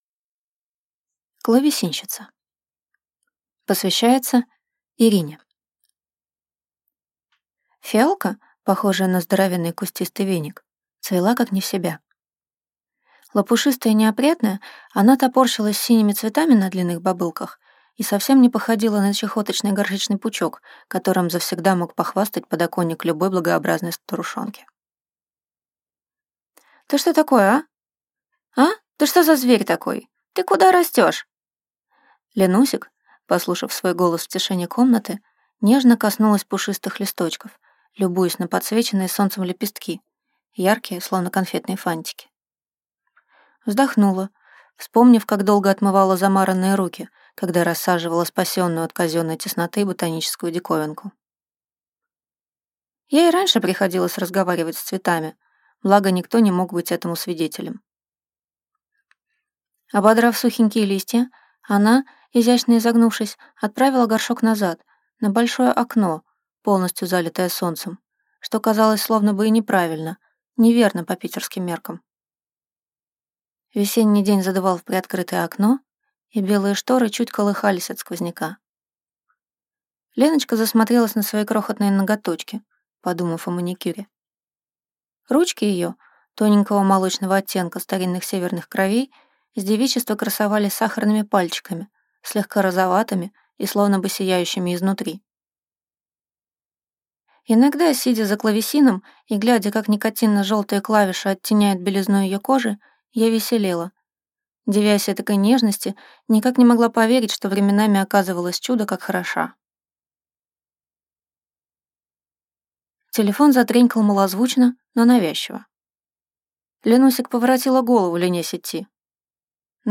Аудиокнига Клавесинщица | Библиотека аудиокниг